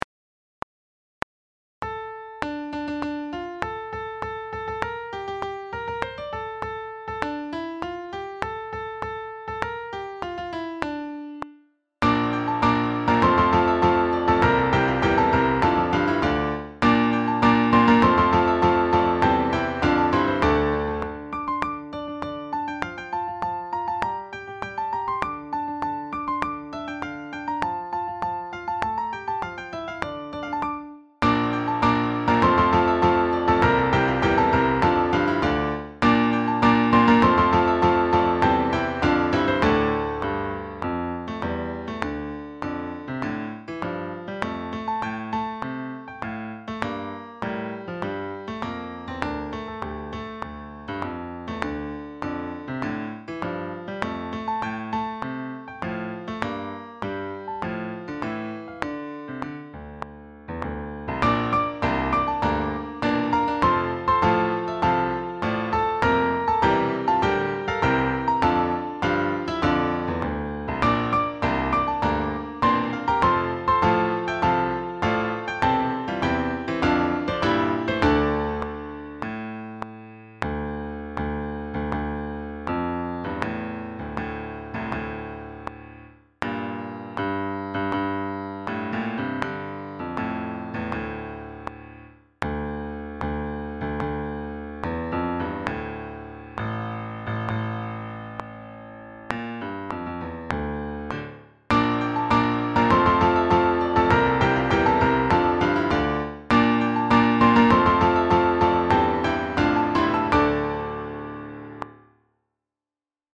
Sax Octets
sSAATTBbDuration:
This simple arrangement puts the verses on different combinations of saxes in different styles with the full choir for the chorus.
Backing track
157-4-wellerman8-backing-track.mp3